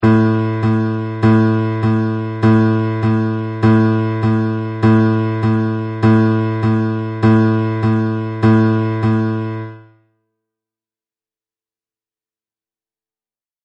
1. Temps binaire :
Chaque temps binaire aura une sous pulsation en son milieu.